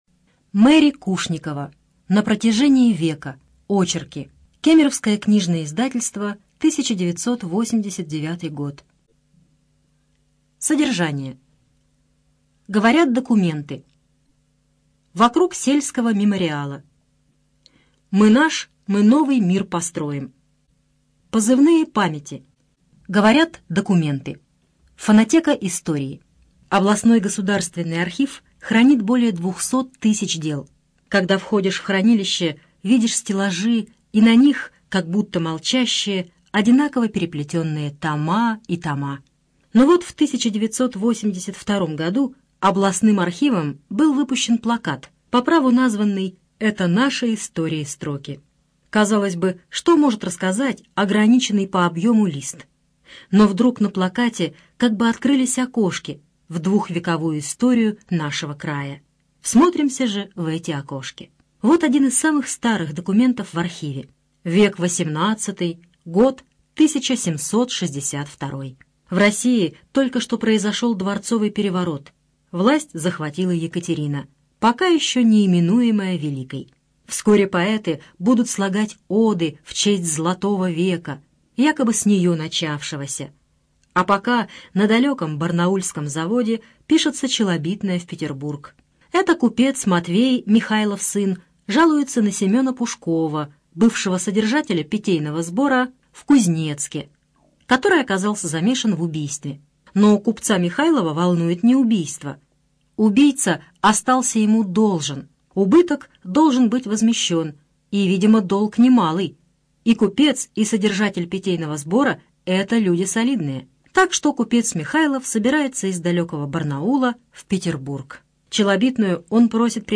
Студия звукозаписиКемеровская областная специальная библиотека для незрячих и слабовидящих